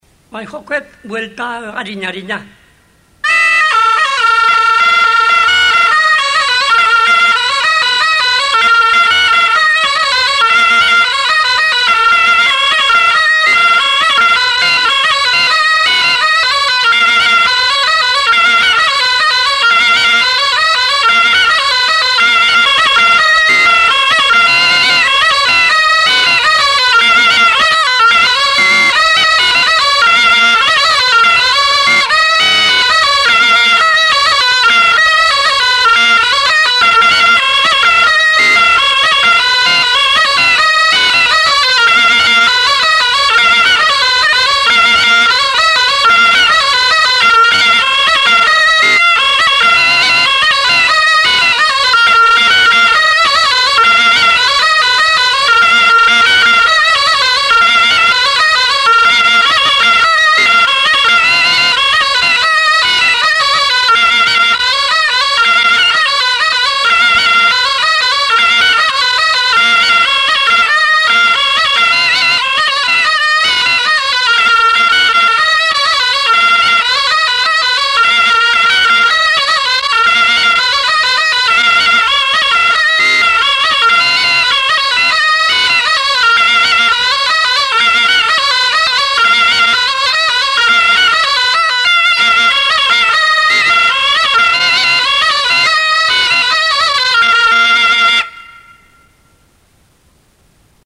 Aerophones -> Reeds -> Single fixed (clarinet)
EUROPE -> EUSKAL HERRIA
ALBOKA
Klarinete bikoitza.